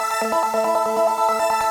Index of /musicradar/shimmer-and-sparkle-samples/140bpm
SaS_Arp02_140-A.wav